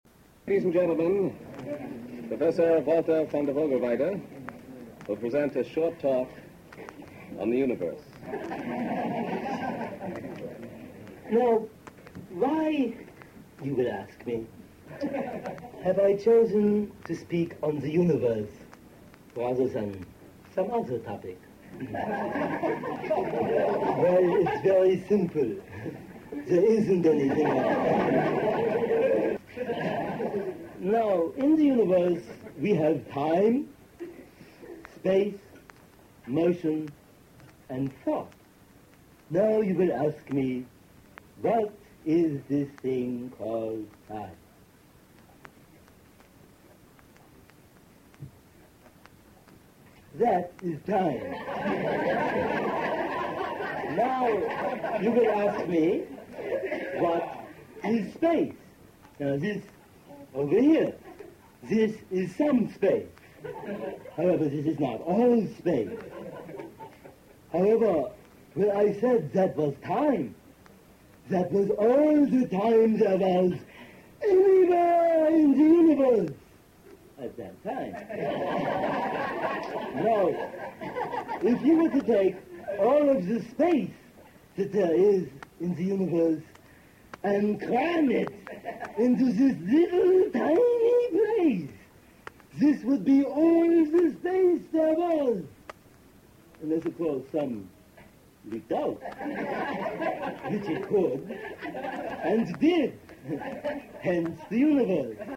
. The kind of improvisation that this slightly edited extract comes from began in the back of a bar called the Compass in Chicago’s Hyde Park neighborhood near the University of Chicago campus.